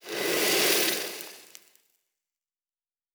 Blacksmith 08.wav